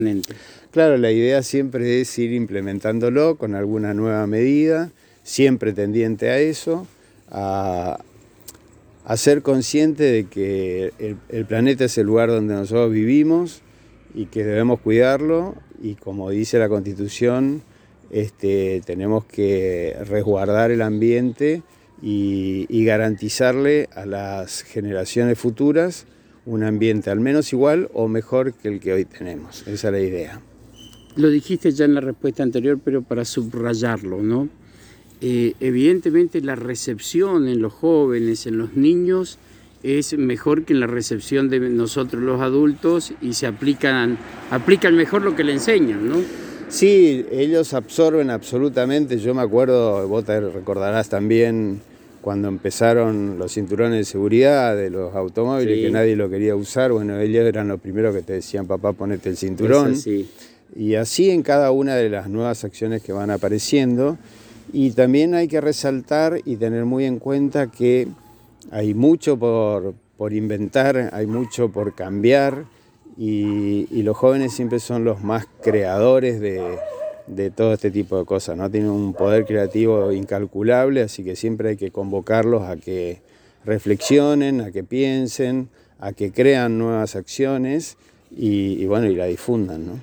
Alfredo Fredy Rau Secretario de Medio Ambiente de Apóstoles en charla exclusiva con la ANG manifestó sobre las charlas de concientización en las Escuelas comenzando por la Escuela N° 71 del Barrio Estación.